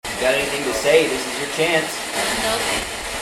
LOS ANGELES POLICE MUSEUM - EVP'S FEBRUARY 2021